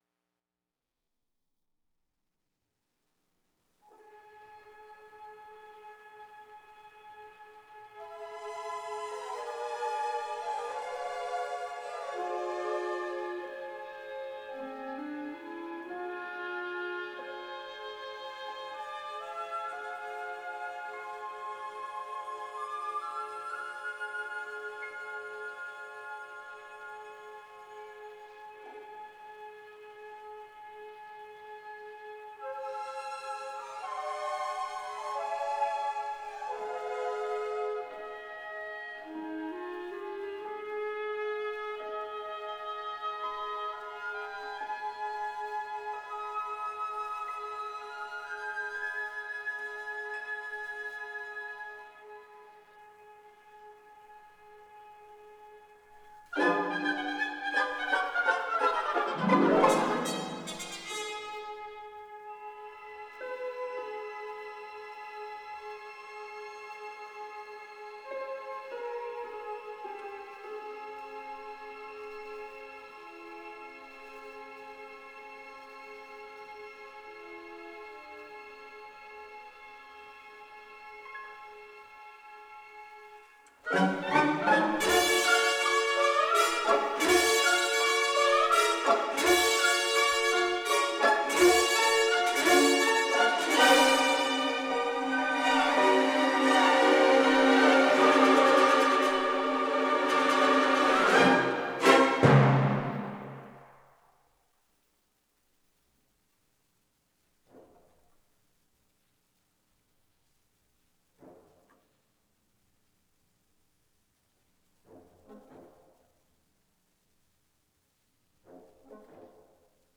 recorded in Symphony Hall, Boston